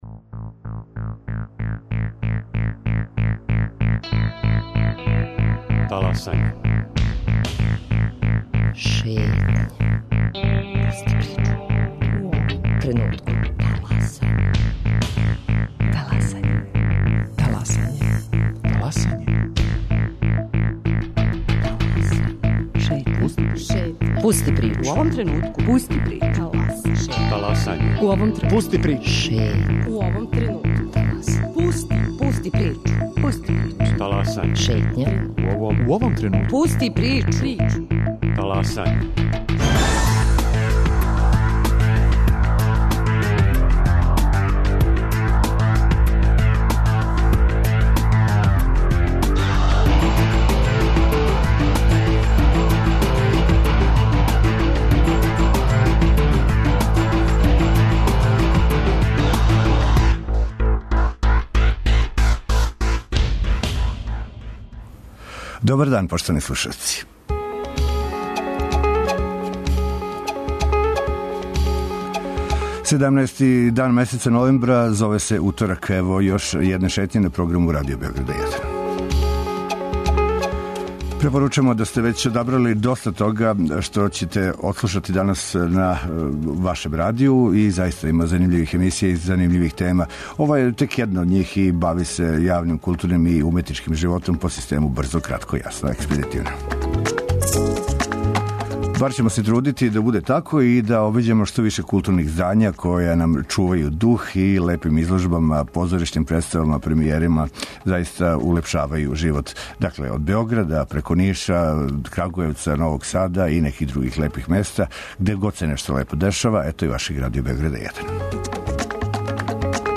И овог уторка у Шетњи серија информација, најава и кратких разговора у вези са актуелним дешавањима у свету културе.